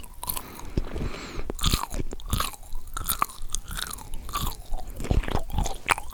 action_eat_2.ogg